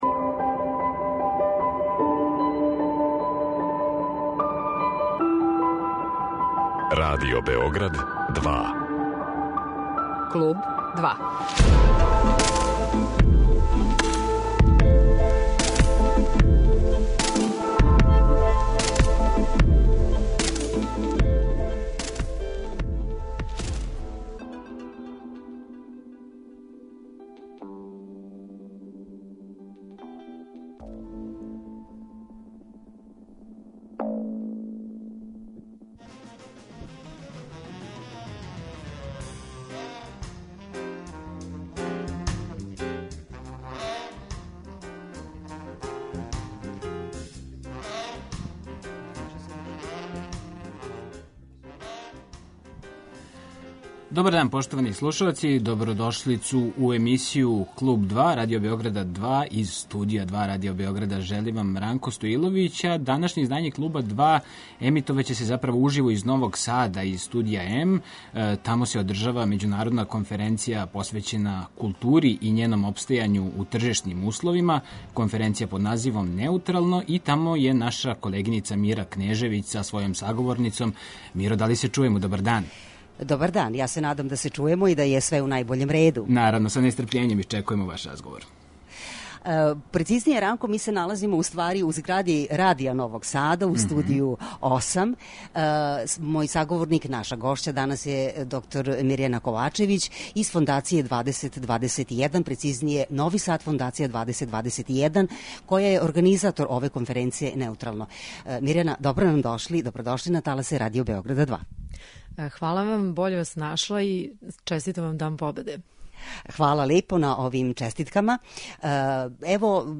Данашње издање Клуба 2 емитује се уживо из Новог Сада, из Студија М, где се одржава и Међународна конференција посвећена култури и њеном опстајању у тржишним условима. Конференцију организује Фондација Нови Сад 2021 - Европска престоница културе.